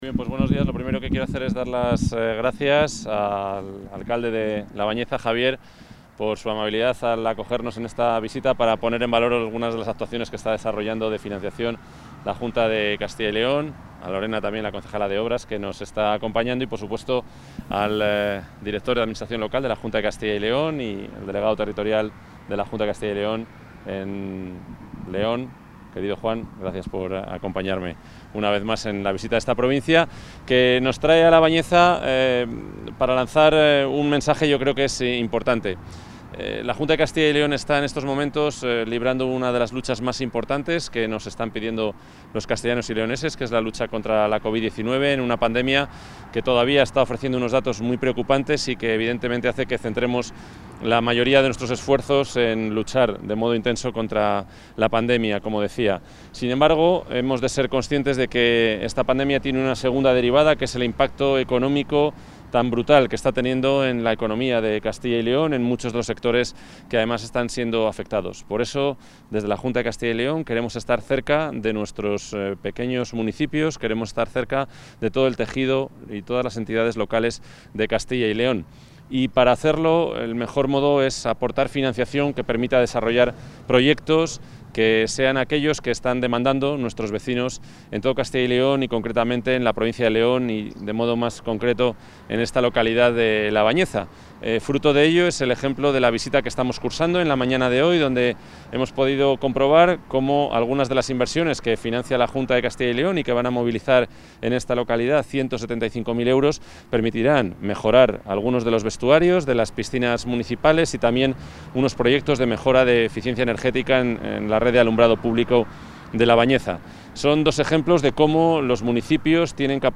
Intervención del consejero de la Presidencia.